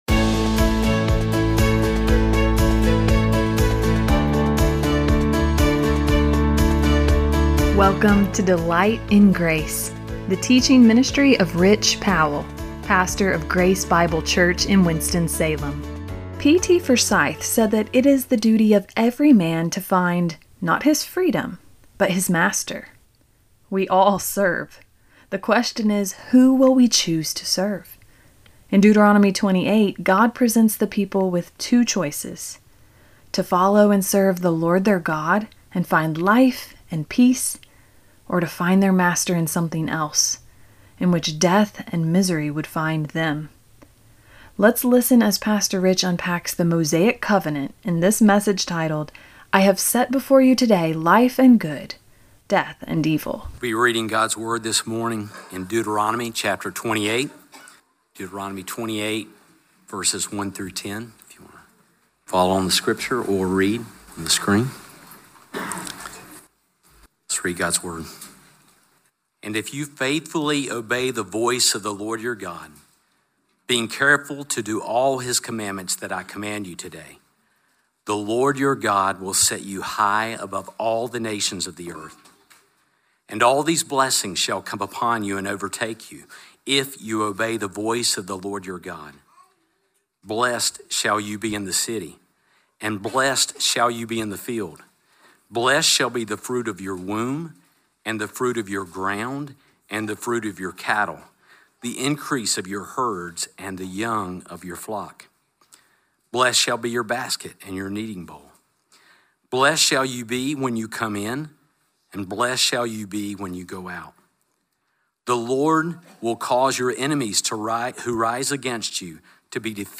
Life and Death, Good and Evil 1 Podcast with Grace Bible Church